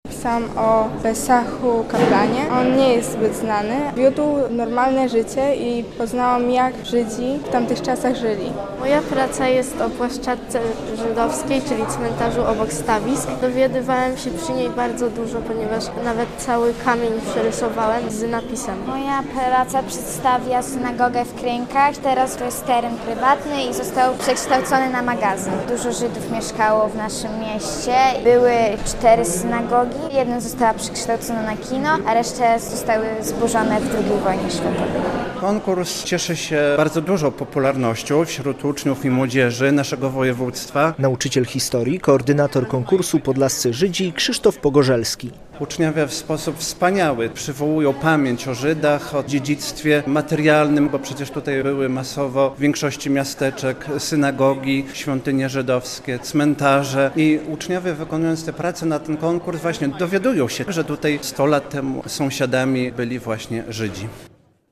Finał konkursu "Podlascy Żydzi" - relacja